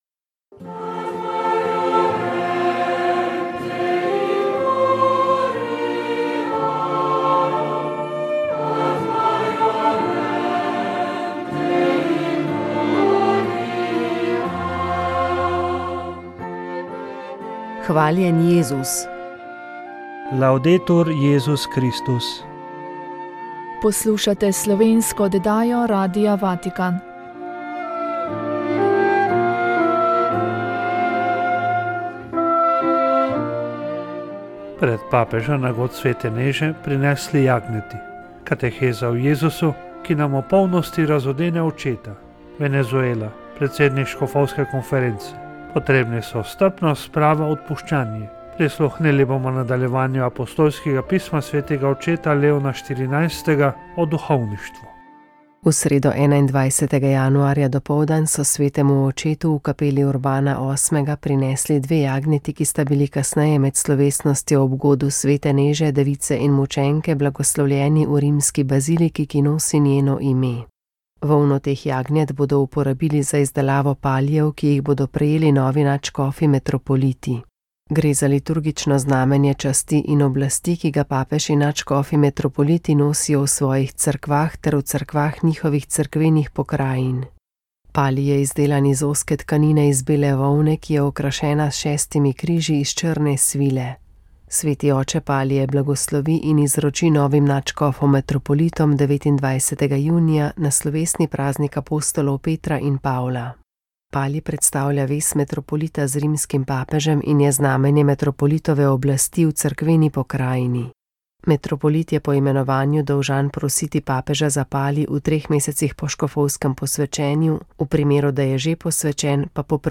Sveta maša
Sv. maša iz stolne cerkve sv. Janeza Krstnika v Mariboru dne 7. 2.
Iz mariborske stolnice smo na peto nedeljo med letom neposredno prenašali sveto mašo, ki jo je daroval tamkajšnji nadškof Alojzij Cvikl.